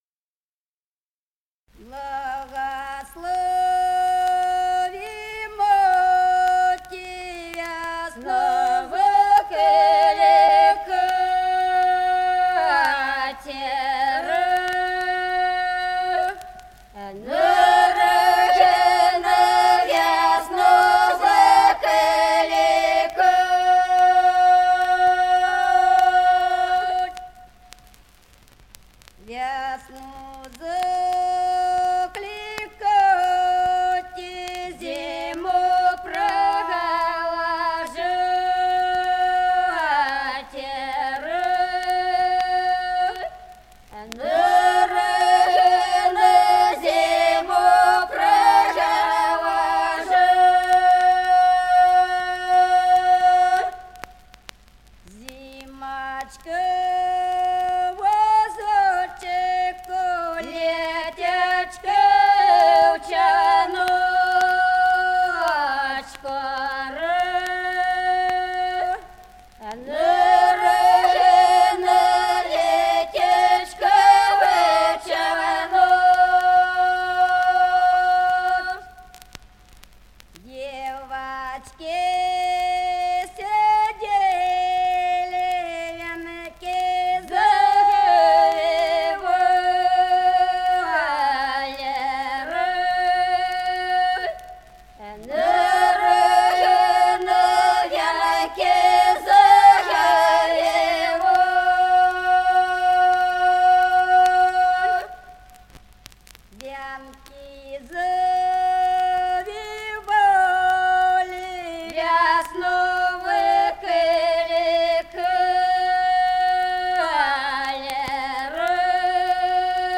Народные песни Стародубского района [[Описание файла::«Благослови, мати», весняная девичья.
Записано в Москве весной 1966 г., с. Остроглядово.
— (Поют народные исполнители).